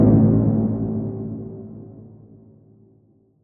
hollow rock honk
Horn.mp3